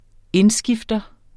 Udtale [ ˈenˌsgifdʌ ]